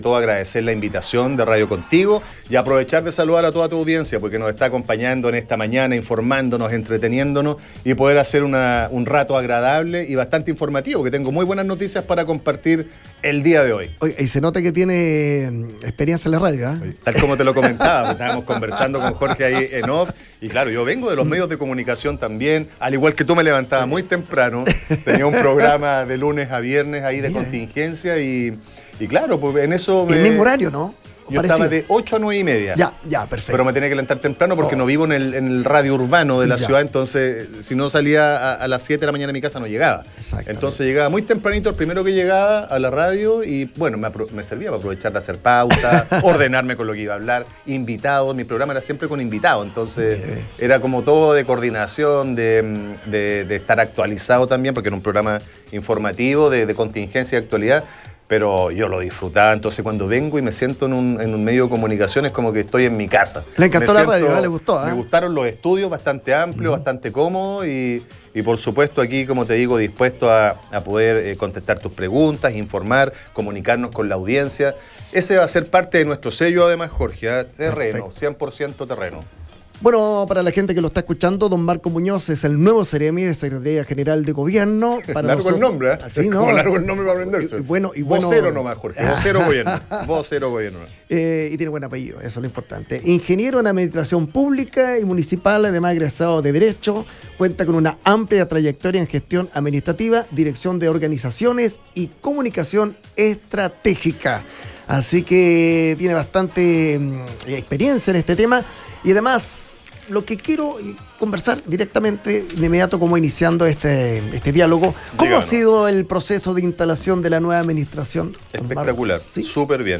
Seremi de Gobierno de Ñuble Marco Muñoz conversa en Radio Contigo sobre su gestión y apoyo a organizaciones - Contigo